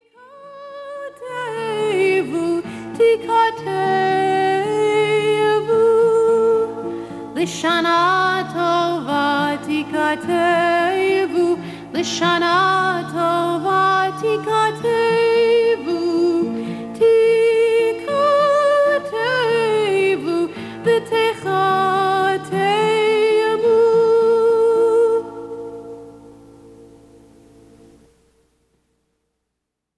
A Jewish sing-a-long for families!